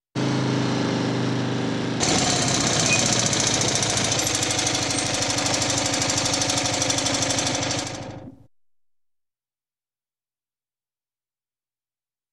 Звуки сломанной машины
Двигатель машины резко выходит из строя в пути с дребезжащим звуком